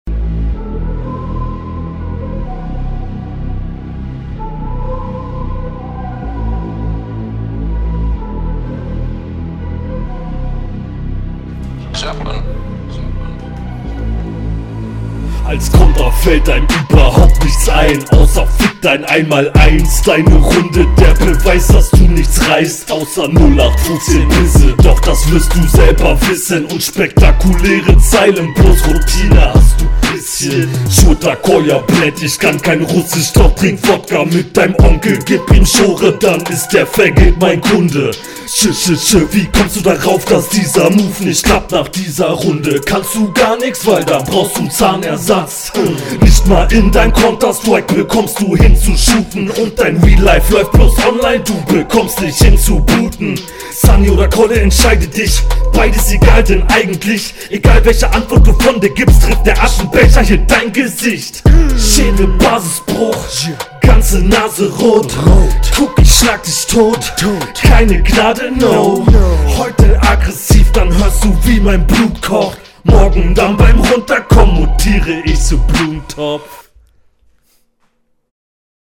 Ui also du rappst auch gut auf dem Beat, dein Gegner ist allerdings um einiges …